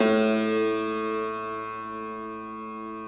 53k-pno05-A0.wav